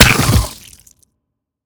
biter-death-1.ogg